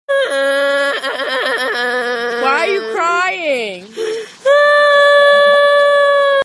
BEST CRYING
why-you-crying-vine.mp3